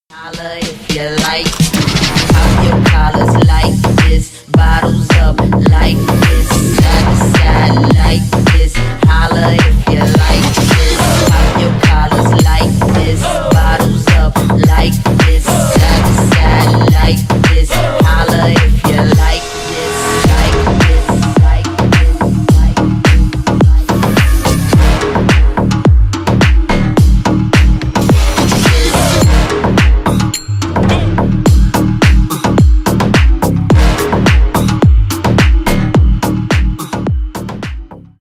Ремикс # Рэп и Хип Хоп # Танцевальные
громкие